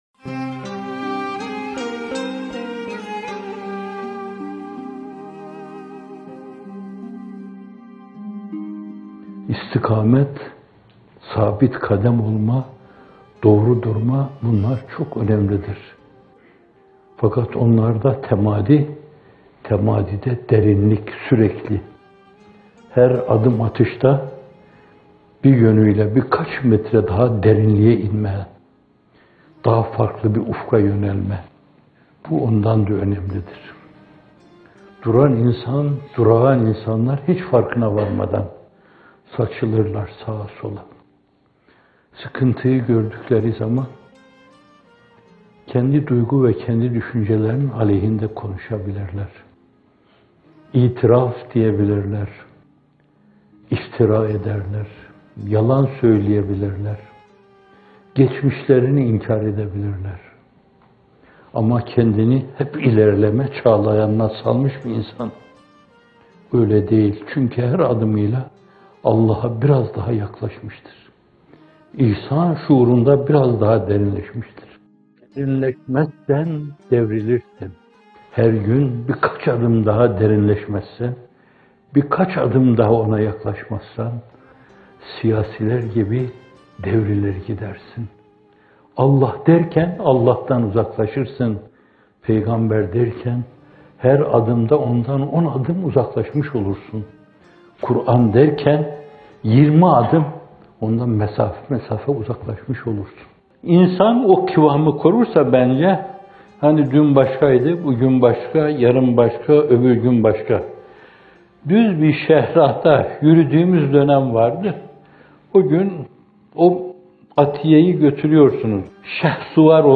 - Fethullah Gülen Hocaefendi'nin Sohbetleri